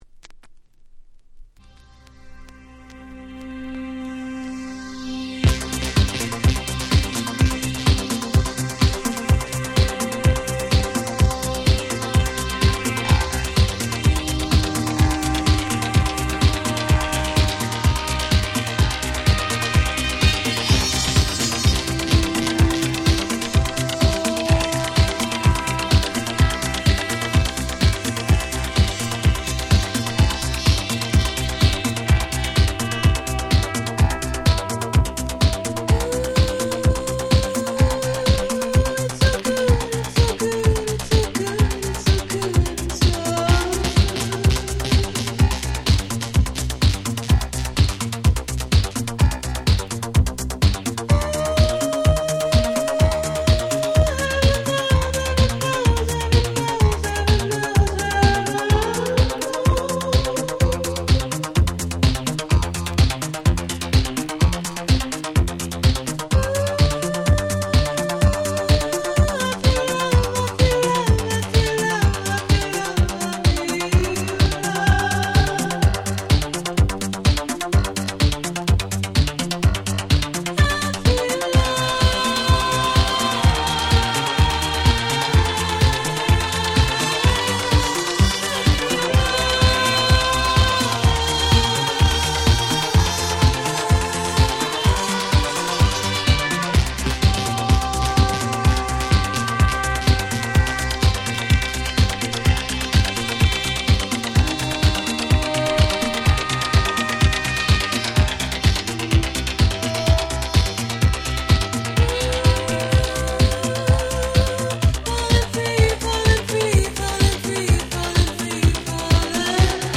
「これ本当に82年？？」と感じてしまう程に「House」を感じさせる本当に凄いRemix。
Hi-Nrgクラシックスとしてもお馴染みです。